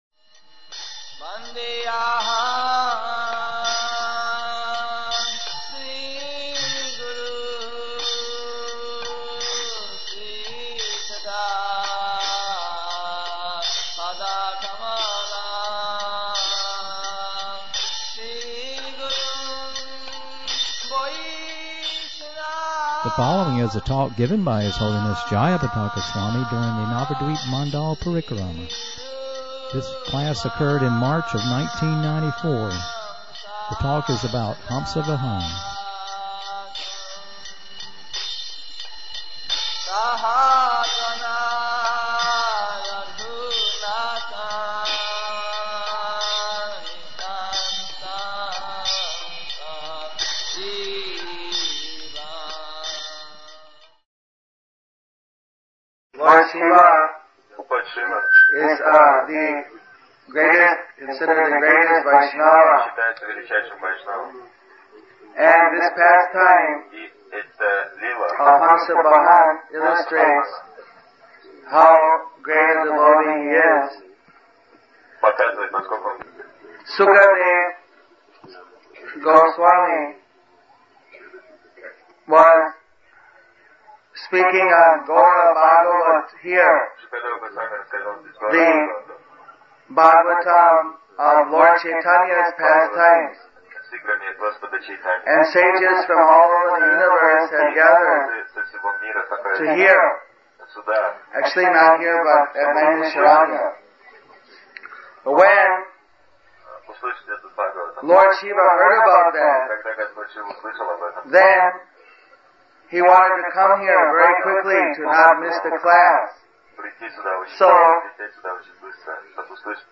199403 Navadvipa Mandala Parikarma Hamsavahan